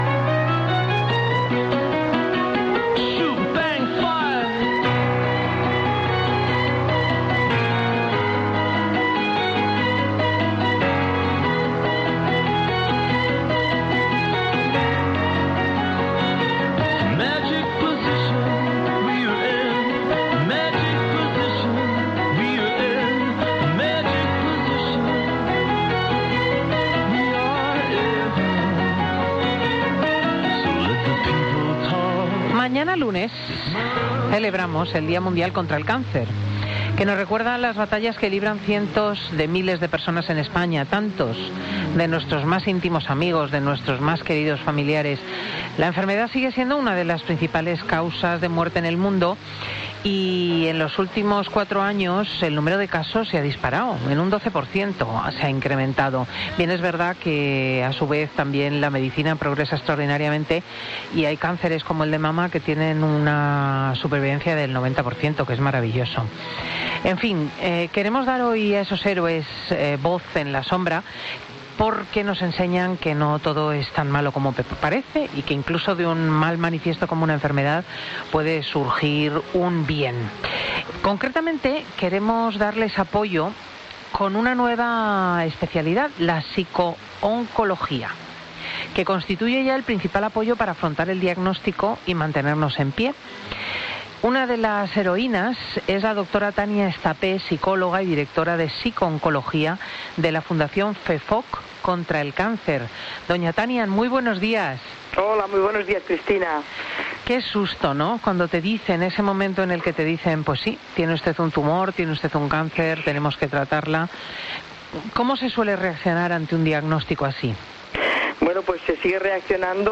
FIN DE SEMANA . Presentado por Cristina López Schlichting, prestigiosa comunicadora de radio y articulista en prensa, es un magazine que se emite en COPE , los sábados y domingos, de 10.00 a 14.00 horas, y que siguen 769.000 oyentes , según el último Estudio General de Medios conocido en noviembre de 2017 y que registró un fuerte incremento del 52% en la audiencia de este programa.